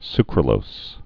(skrə-lōs)